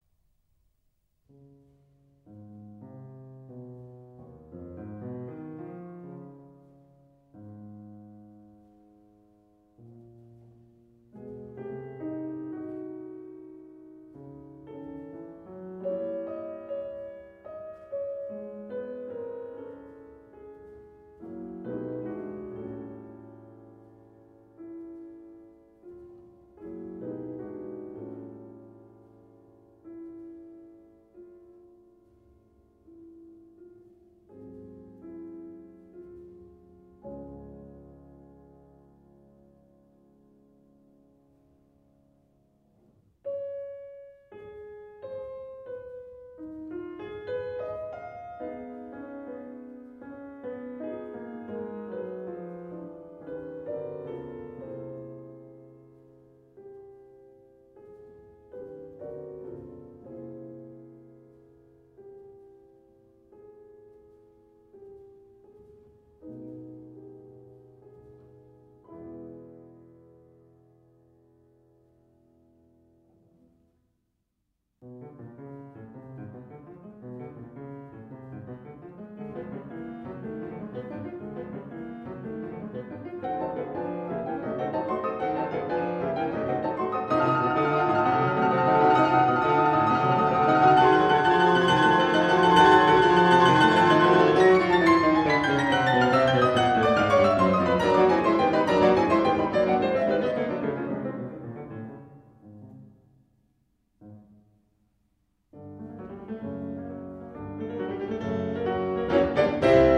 String Quartet in G minor
Très modéré – Très mouvementé et avec passion